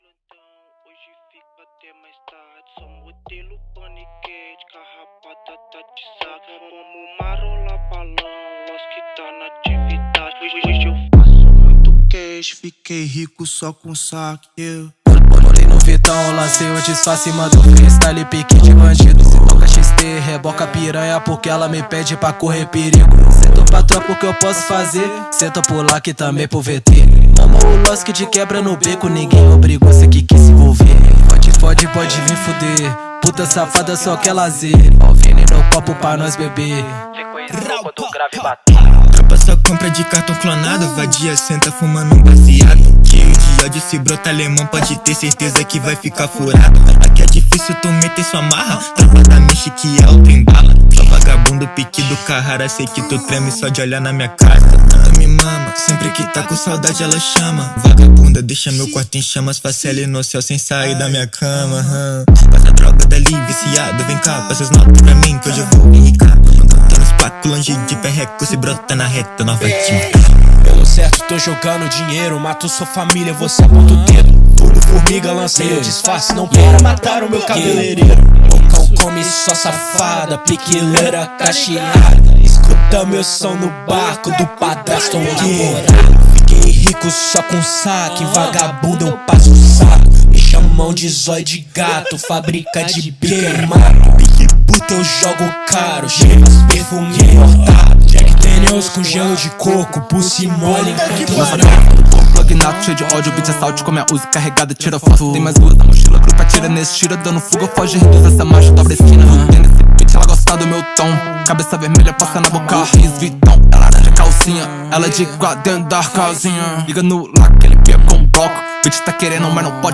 2024-11-04 16:22:52 Gênero: Trap Views